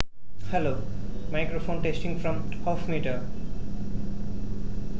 正如我前面提到的那样，人的声音是作为一种投入而发出的。
我将在此共享捕获的文件，配置为采样频率为16k，MCLK为4.096M，经过不同范围(米)的测试。
据分析，如果输入信号(人声)超过0.5米，我们不会收到任何溢出中断。